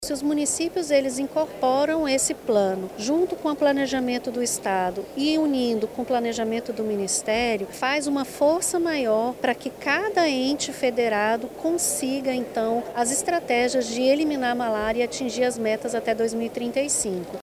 SONORA-2-OFICINA-ERRADICACAO-MALARIA-.mp3